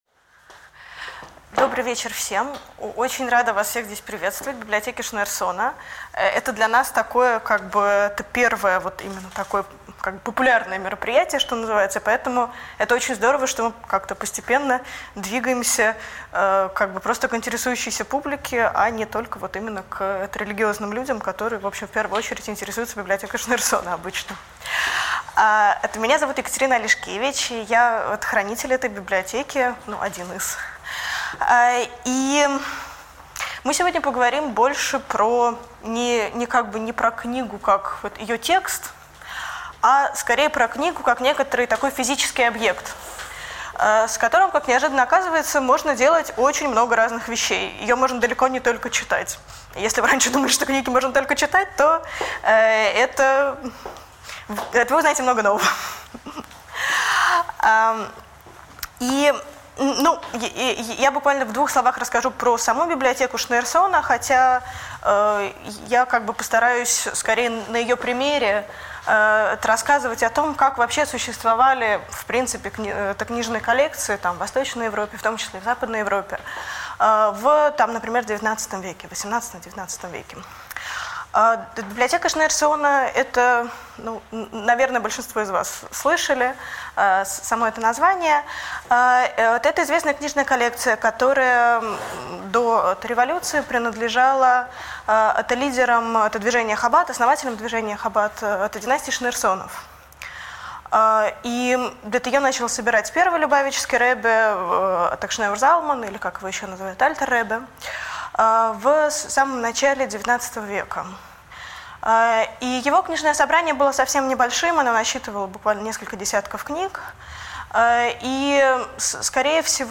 Аудиокнига Книжная коллекция Шнеерсона | Библиотека аудиокниг